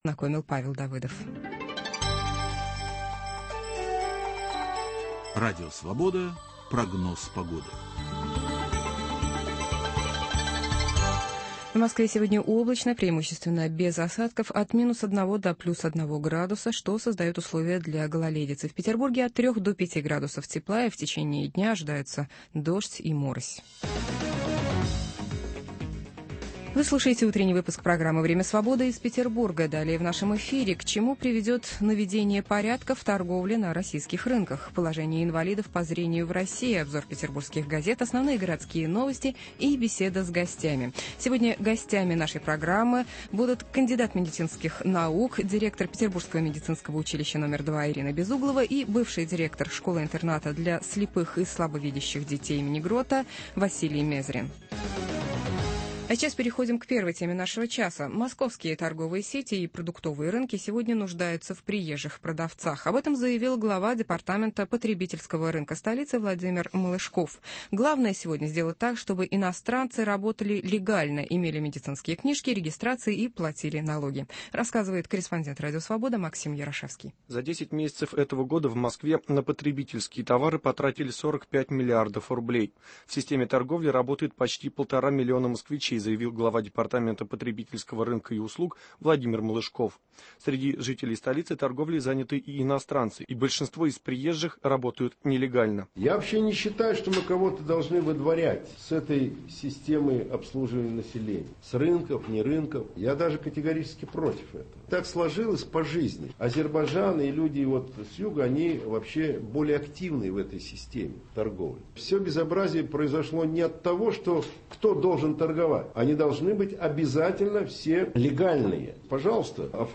Образование и профессиональная адаптация инвалидов по зрению: проблемы и пути решения. Гость в студии